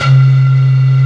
SYN SYN LEAD.wav